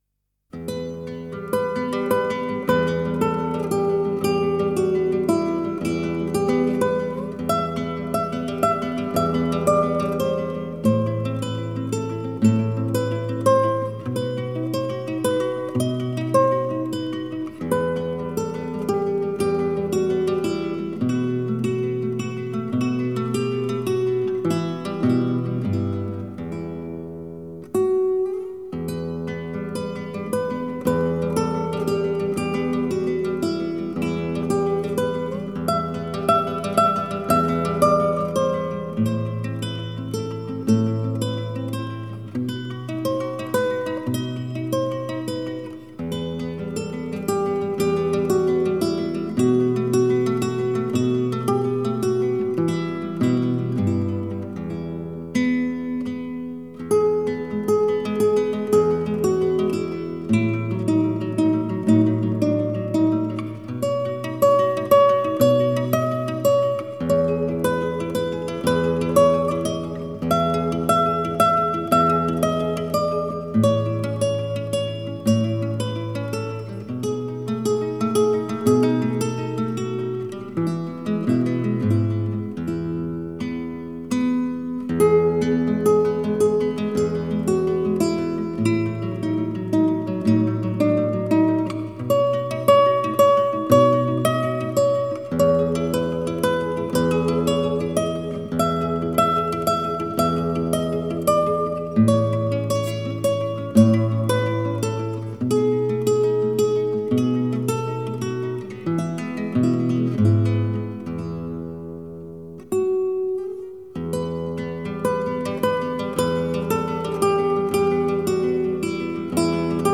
乐曲优美纯朴的旋律与清澈的分解和弦完全溶为一体，充满温柔和浪漫的气息